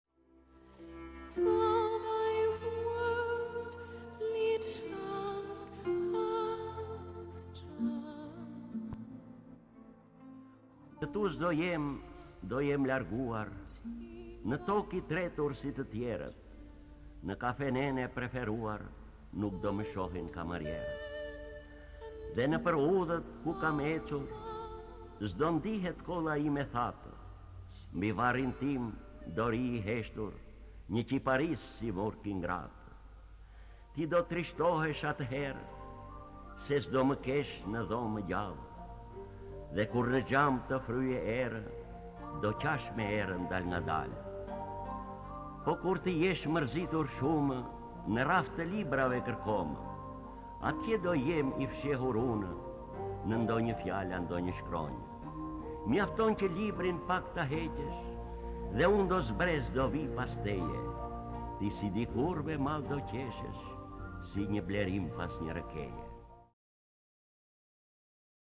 D. AGOLLI - KUR TË JESH MËRZITUR SHUMË Lexuar nga D. Agolli KTHEHU...